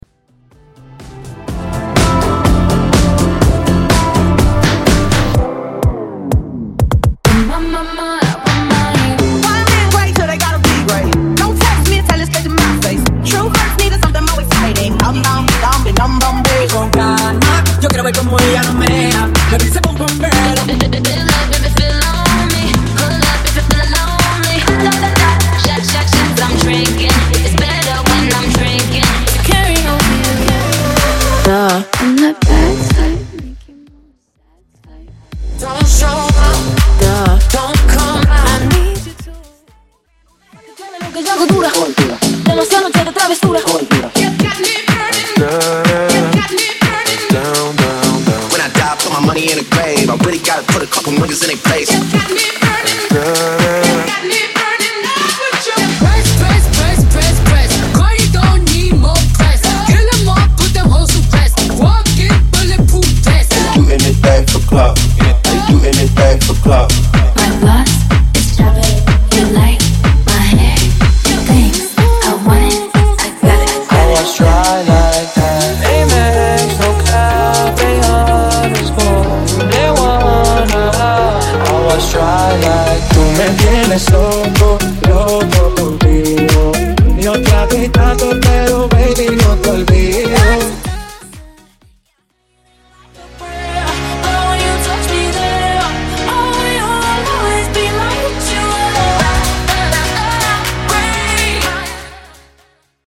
80's , RE-DRUM 124 Clean